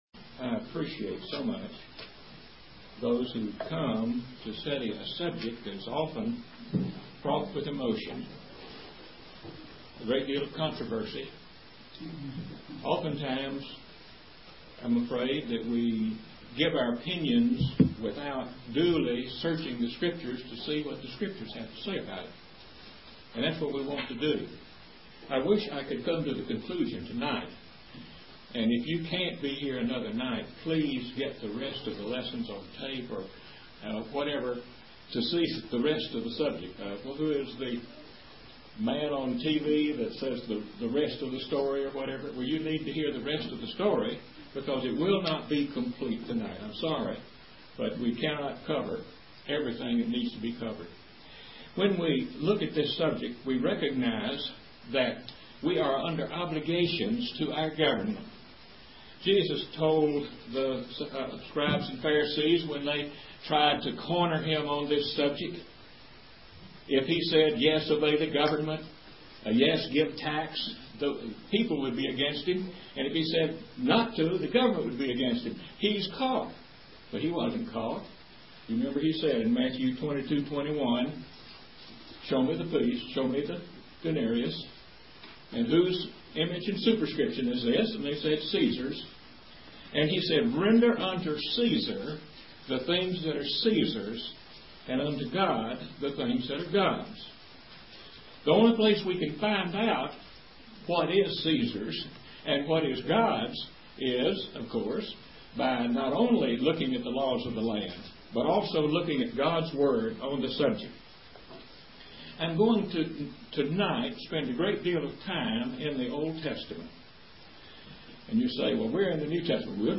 Gospel Meeting Series North Huntsville, Alabama August 12-14, 2005